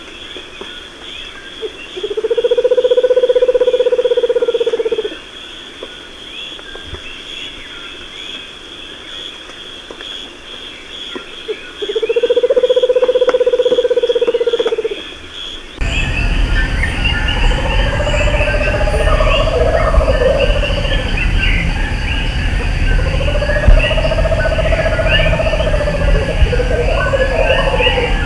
Otus nudipes
mucaritos.wav